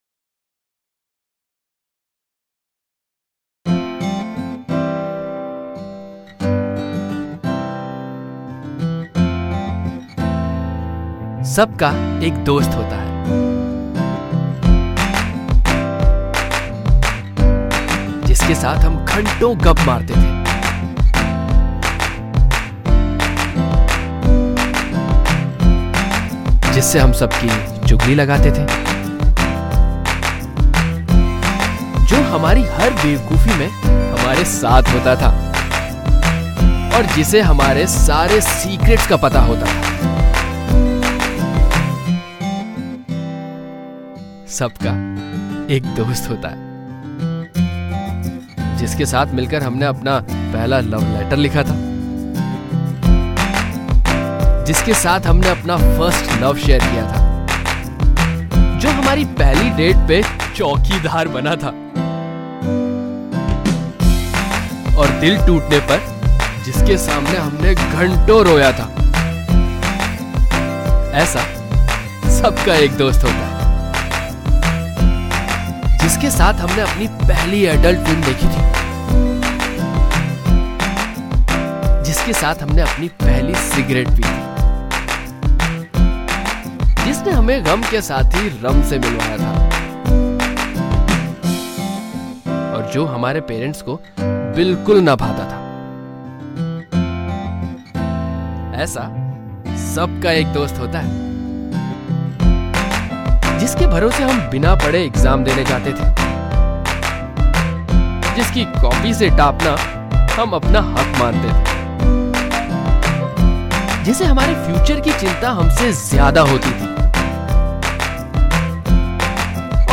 HomeMp3 Audio Songs > Others > Funny Mp3 Songs